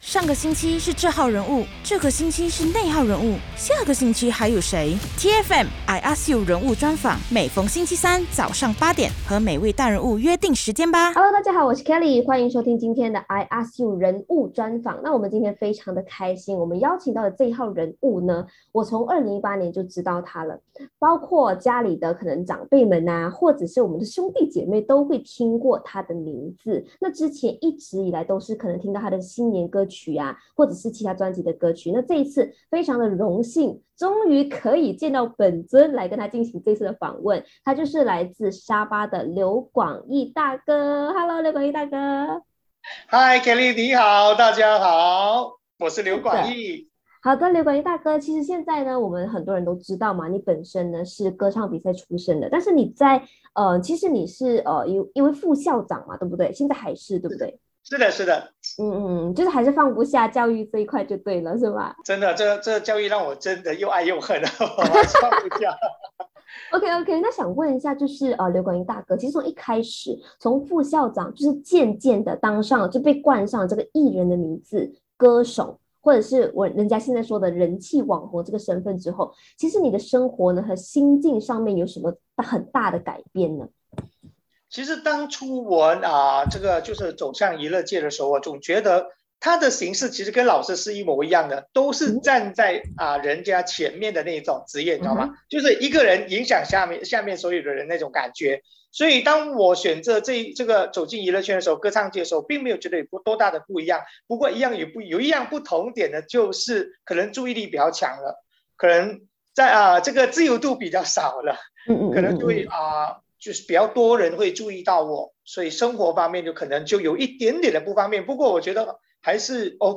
人物专访 东马美发达人